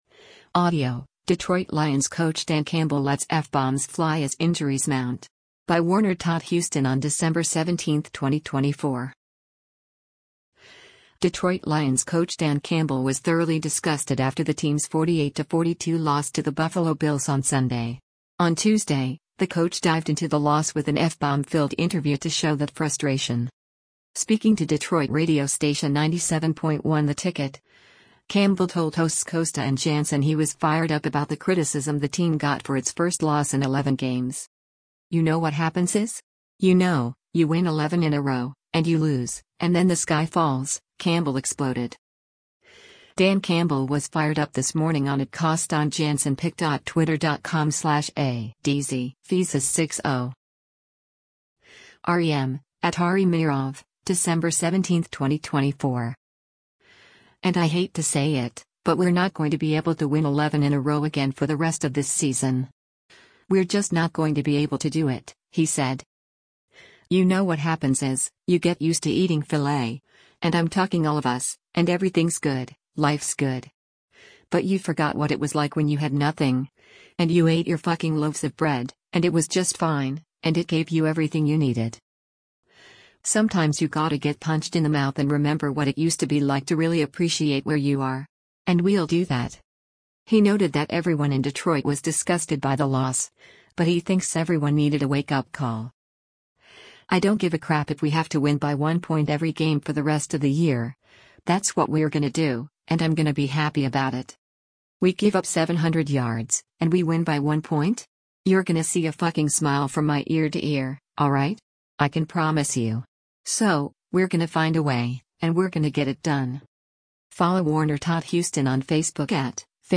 Detroit Lions coach Dan Campbell was thoroughly disgusted after the team’s 48-42 loss to the Buffalo Bills on Sunday. On Tuesday, the coach dived into the loss with an F-bomb-filled interview to show that frustration.